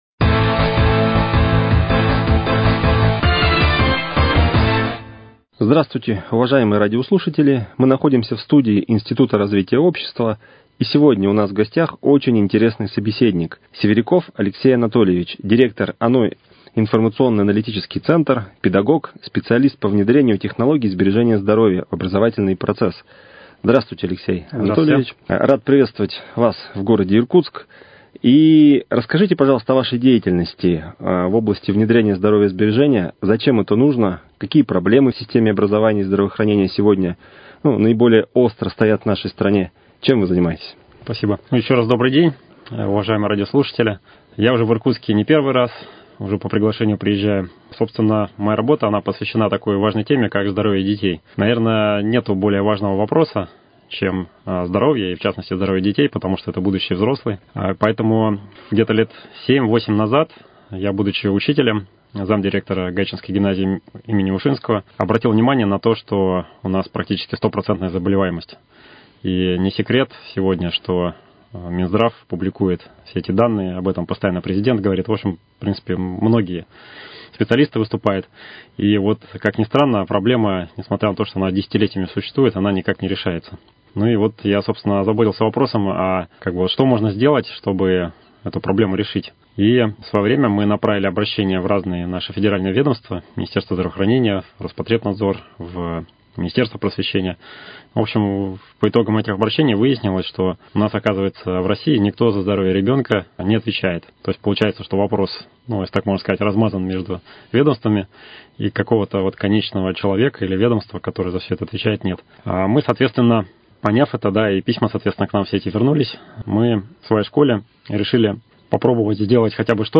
Актуальное интервью: О методике здоровьесберегающей технологии Владимира Базарнова